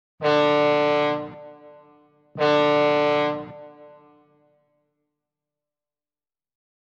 Fog Horn Sound